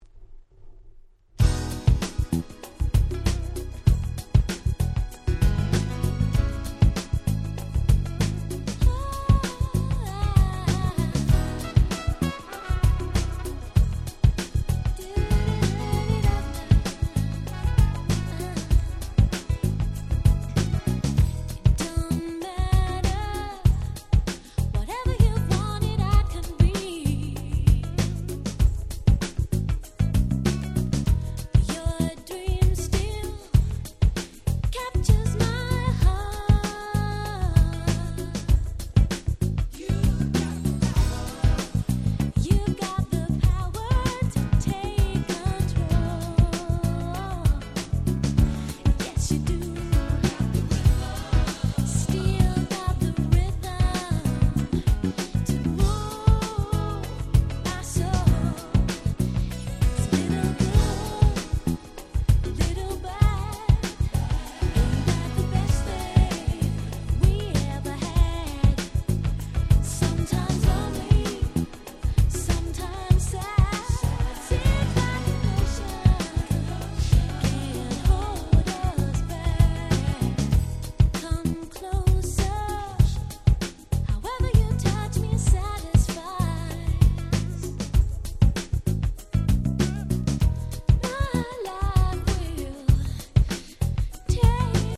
91' Super Nice UK R&B LP !!